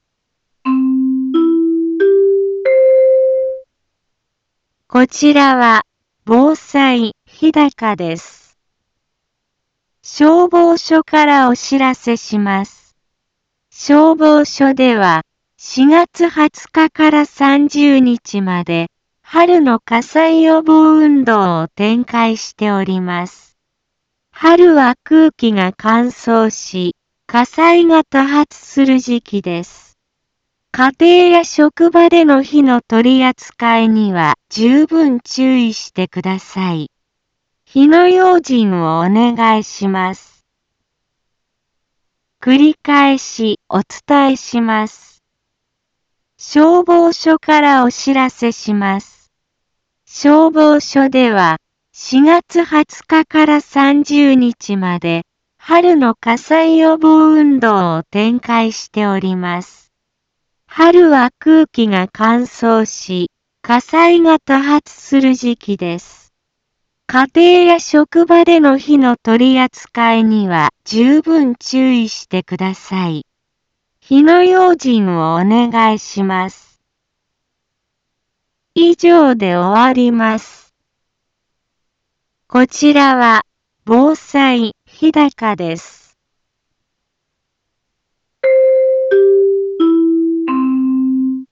BO-SAI navi Back Home 一般放送情報 音声放送 再生 一般放送情報 登録日時：2024-04-22 15:03:15 タイトル：春の火災予防運動について インフォメーション： 消防署からお知らせします。 消防署では4月20日から30日まで、春の火災予防運動を展開しております。